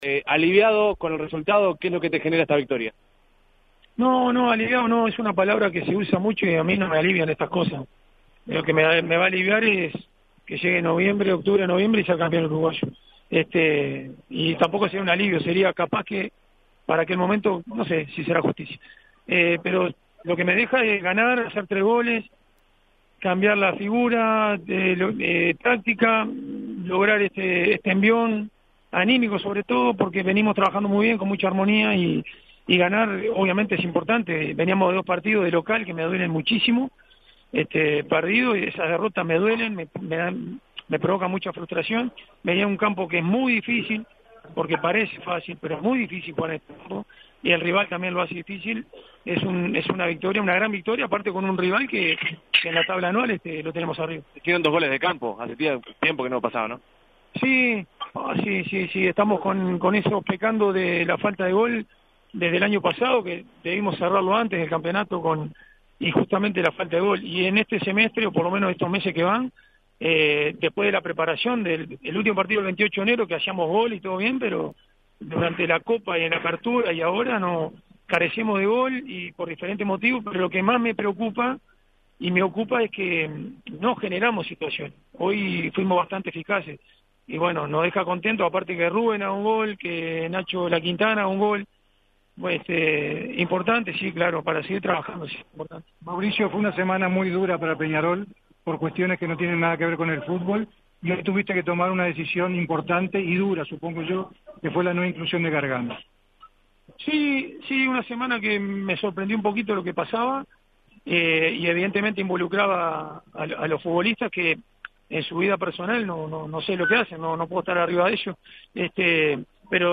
El entrenador de Peñarol, Mauricio Larriera, habló con los medios en el Estadio Domingo Burgueño Miguel, luego del triunfo por 3-1 ante Deportivo Maldonado, en el que se refirió a las claves, la decisión de dejar fuera del plantel a Walter Gargano, y su visión sobre hablar de fútbol ante el Consejo Directivo.
CONF-LARRIERA.mp3